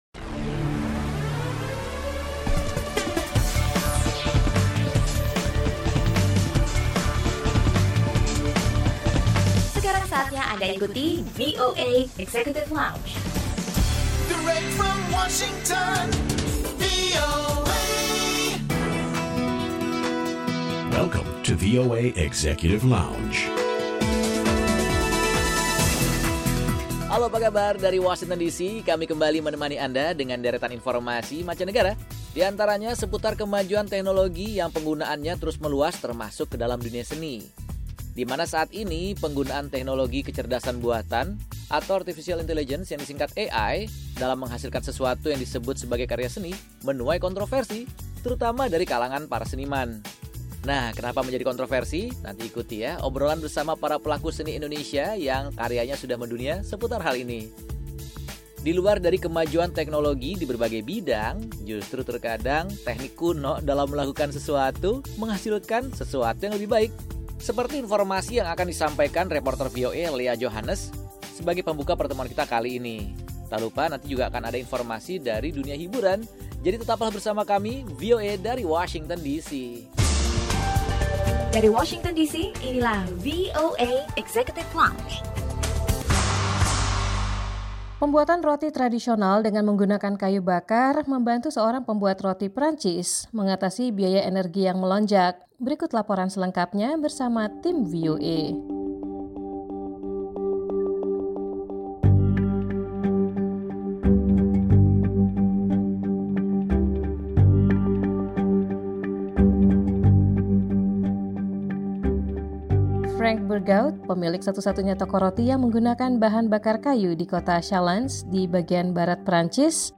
Obrolan bersama dua pelaku seni Indonesia seputar kontroversi AI Art Generator, teknologi kecerdasan buatan yang menghasilkan karya seni, informasi mengenai kesuksesan seorang pembuat roti di Prancis yang memilih untuk menggunakan teknologi memanggang tradisional, serta informasi dari dunia hiburan.